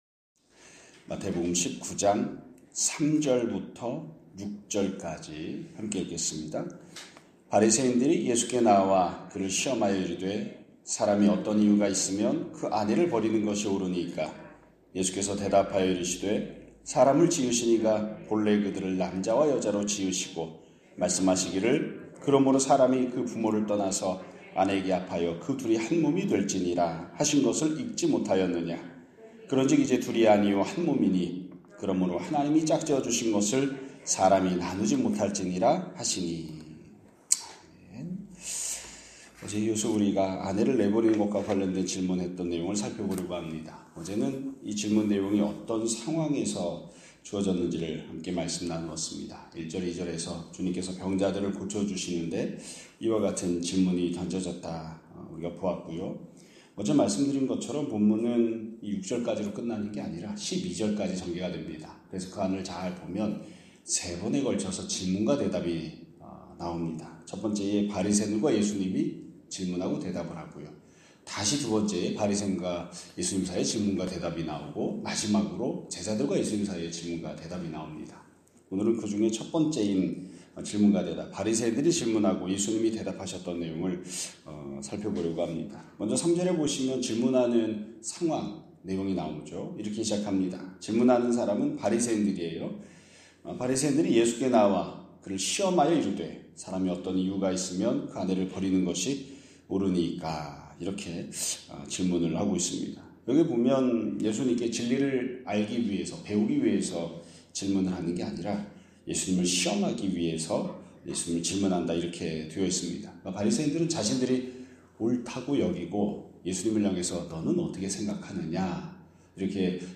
2025년 12월 30일 (화요일) <아침예배> 설교입니다.